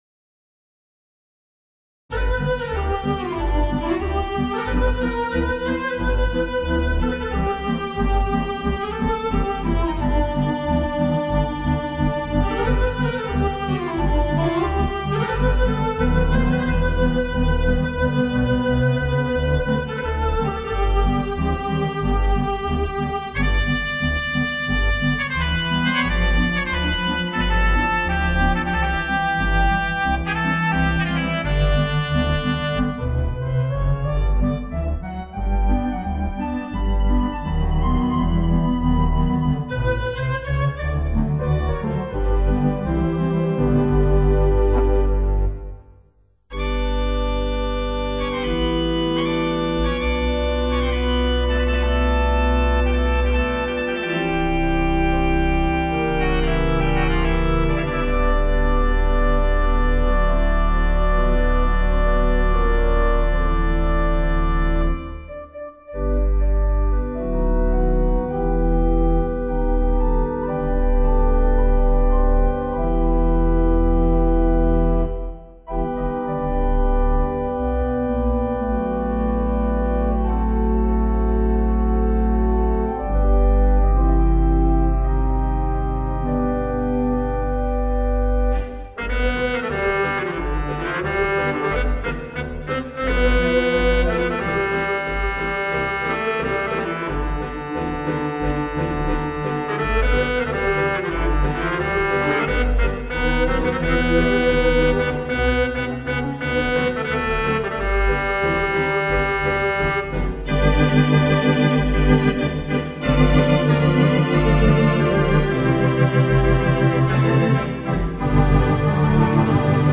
Virtual Orchestral Organ
4/54 Symphonic VI Virtual Orchestral Organ.